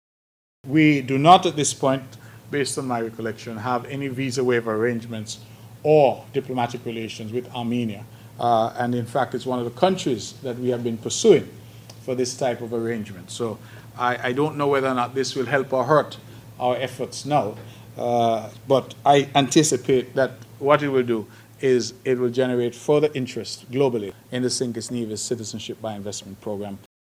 Mr. Brantley gave this response: